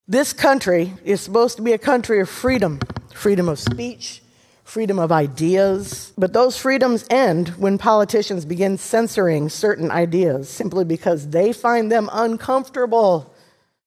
Democrats in the Senate voted against the legislation. Senator Molly Donahue, a Democrat from Cedar Rapids, is a teacher.